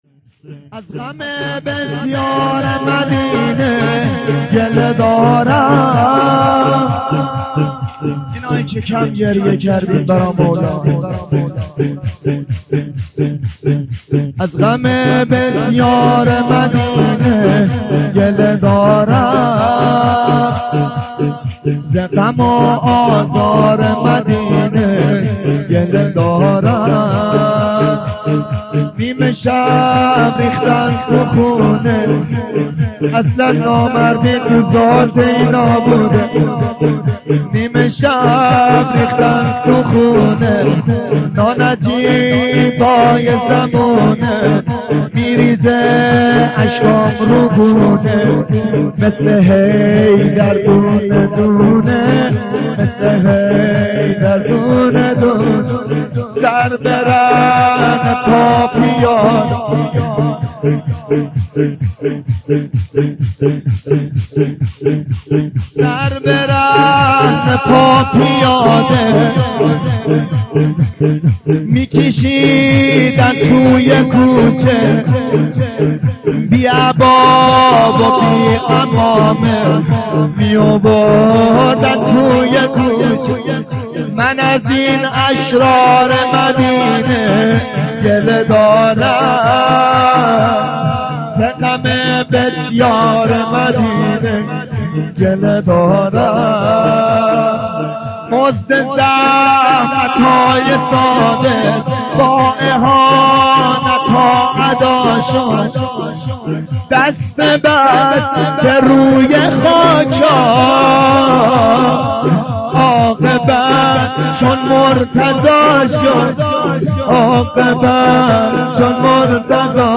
شب شهادت امام صادق(ع) ایام صادقیه(شب دوم) 94/05/19
شور